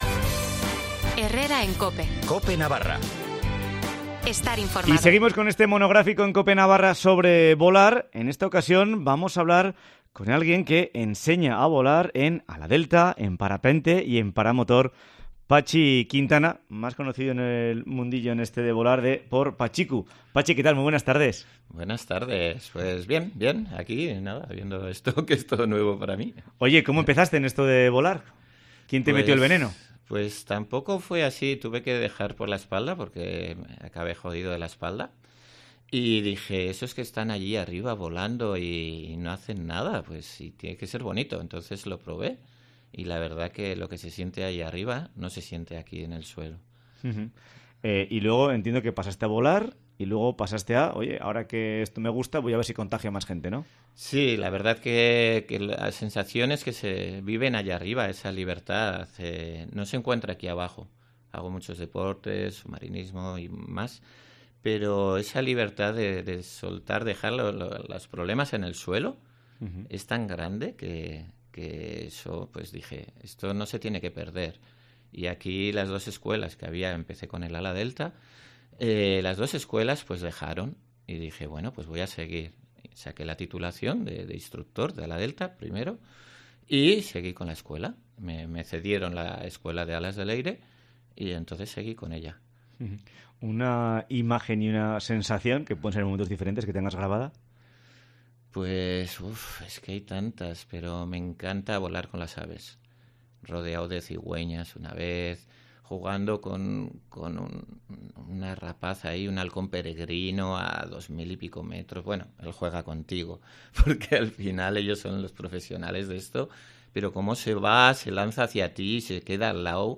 VOLAR EN COPE Entrevista